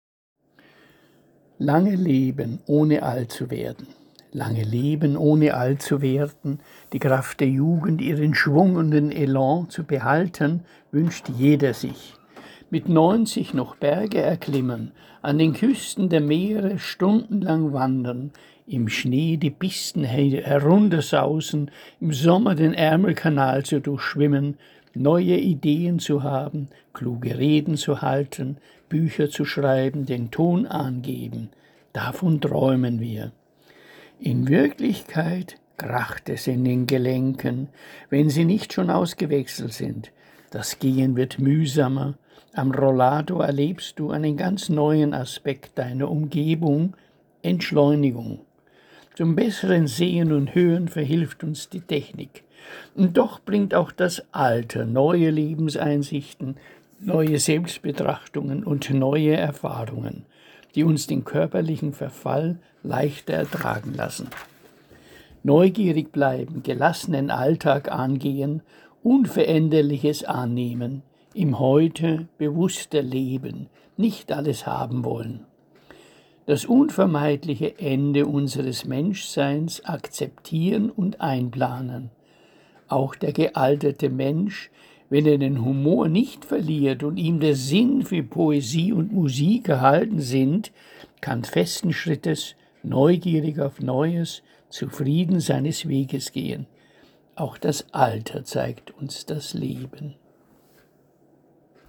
Lesung eigener Gedichte